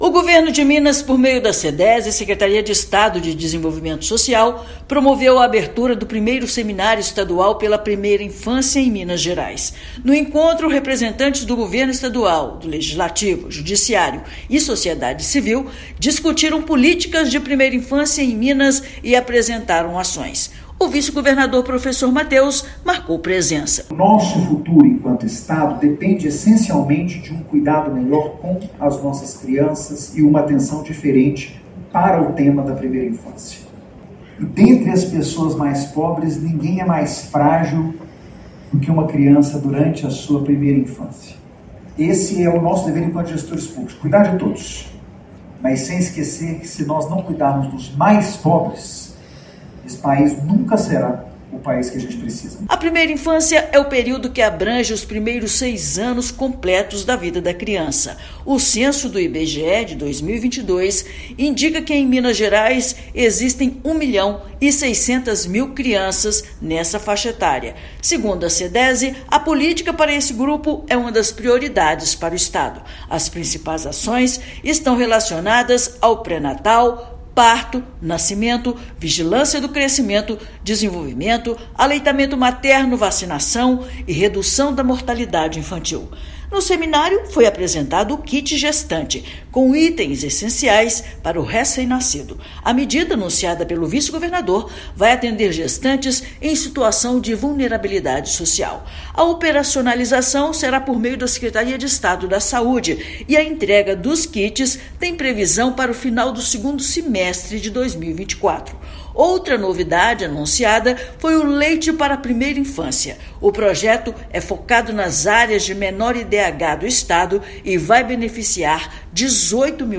[RÁDIO] Governo de Minas apresenta ações em 1º Seminário Estadual pela Primeira Infância
Na abertura, foram detalhadas políticas públicas relacionadas e, também, apresentados projetos focados em alimentação infantil e apoio a gestantes em situação de vulnerabilidade social. Ouça matéria de rádio.